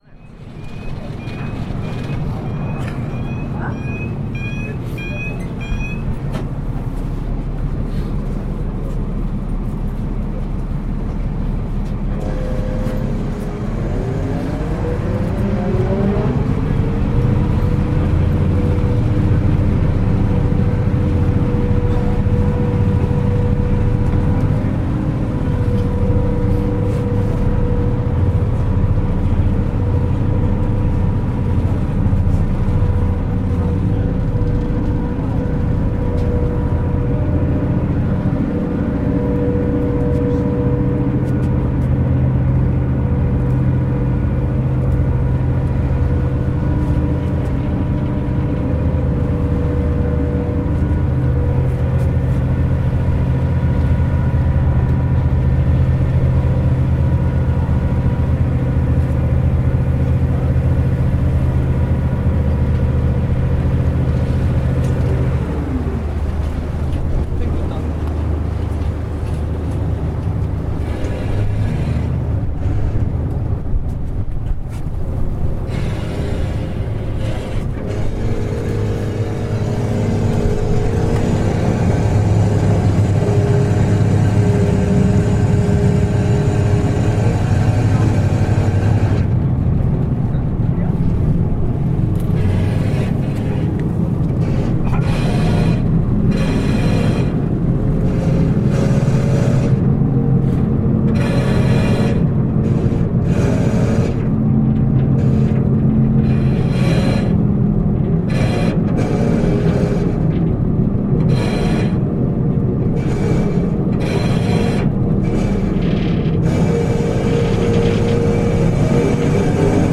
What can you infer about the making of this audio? Recording of the short ferry trip from Amsterdam Centraal station to Amsterdam Noord, November 2015.